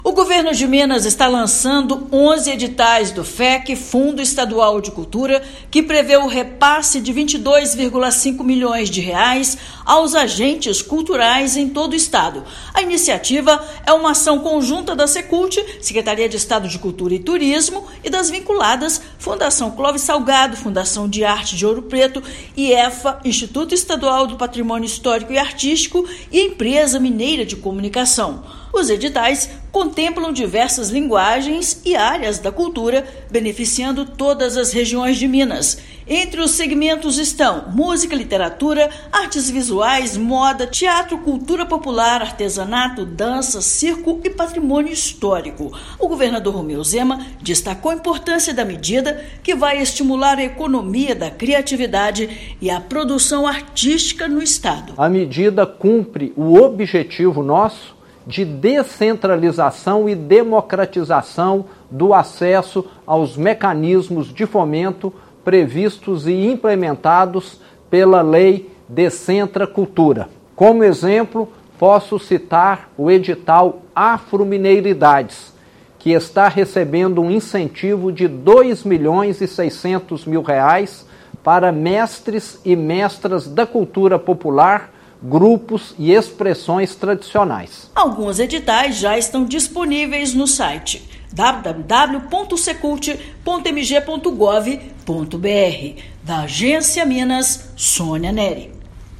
Recursos do Fundo Estadual da Cultura contemplam áreas diversas como música, artes cênicas, literatura, patrimônio histórico, moda e culturas populares. Ouça matéria de rádio.